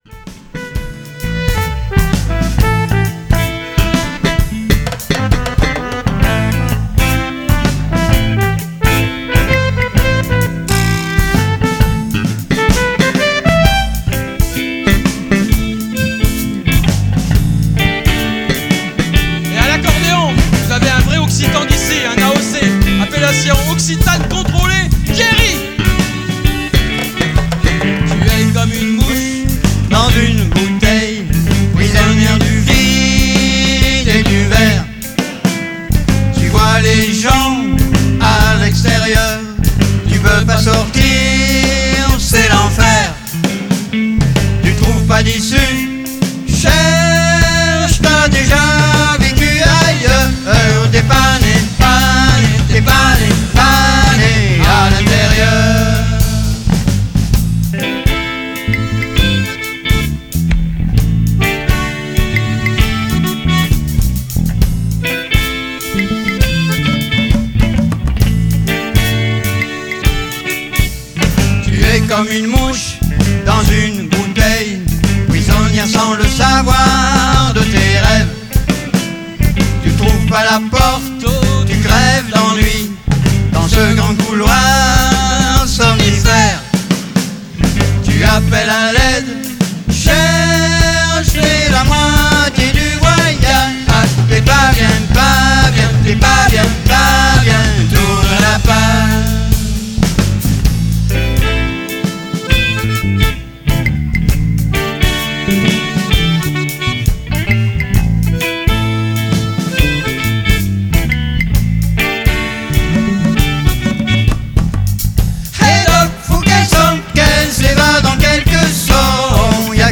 ici au Lembarzique en 2015